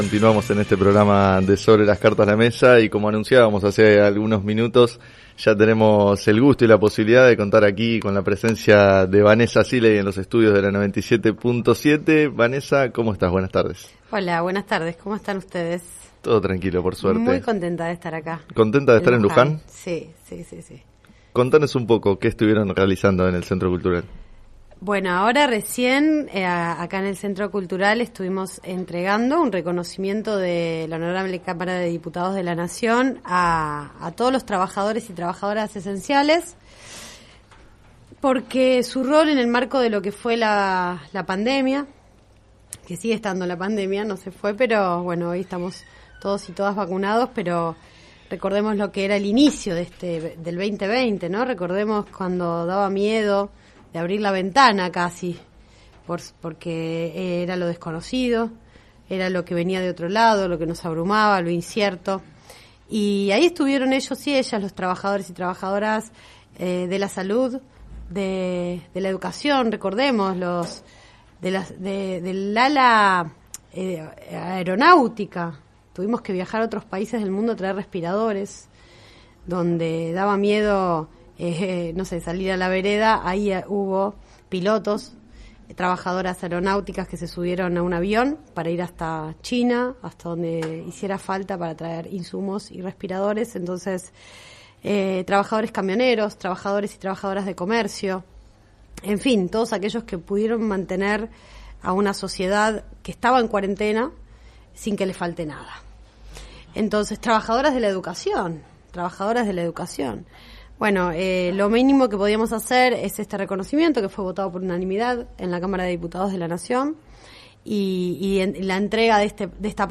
Siley, secretaria general de la Federación de Sindicatos de Trabajadores Judiciales y primera presidenta mujer de la Comisión de Legislación del Trabajo en Diputados, fue entrevistada en el programa “Sobre las cartas la mesa” de FM Líder 97.7, donde expresó su homenaje a quienes cumplieron funciones durante la pandemia.